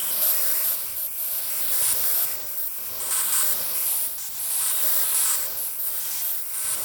Index of /musicradar/stereo-toolkit-samples/Tempo Loops/140bpm
STK_MovingNoiseF-140_02.wav